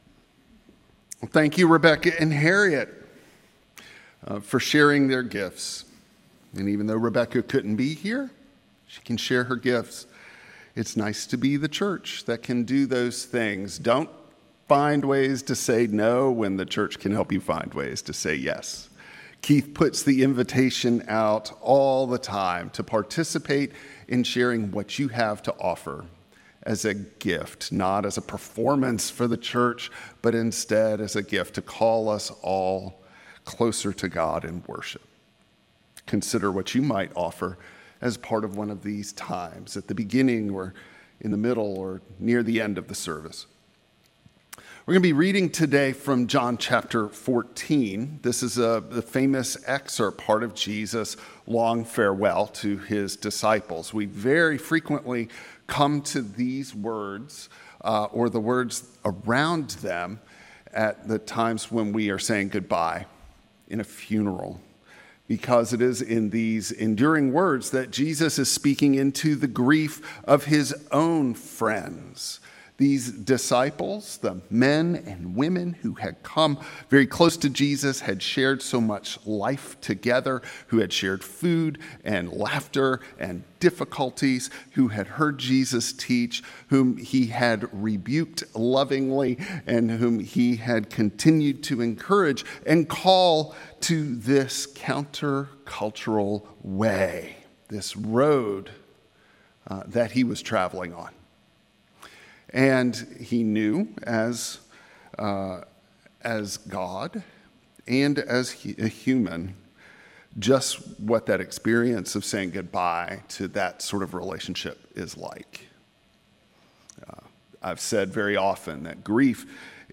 John 14:8-14 Service Type: Traditional Service By getting to know Jesus as a friend